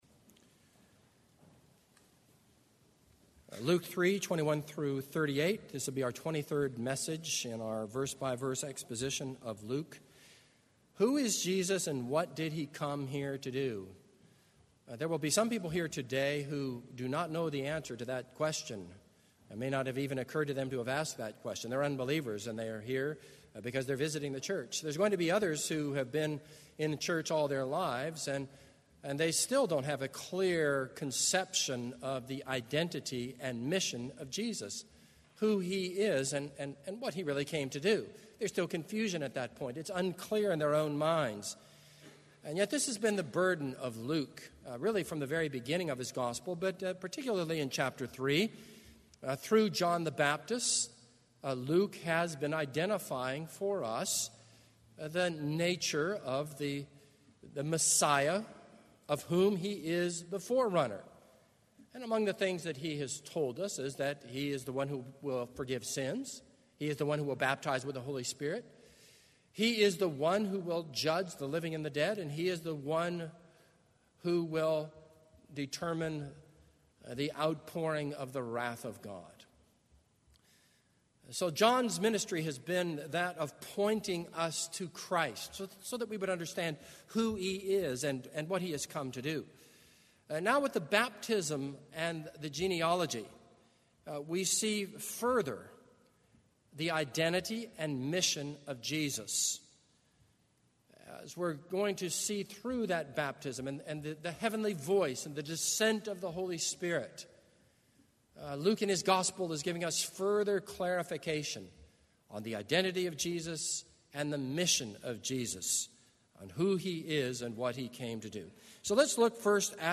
This is a sermon on Luke 3:21-38.